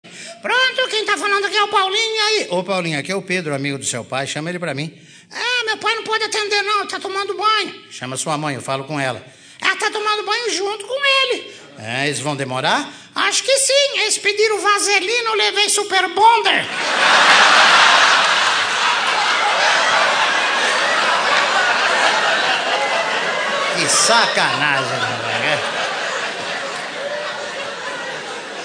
Piada em show ao vivo do comediante Ary Toledo.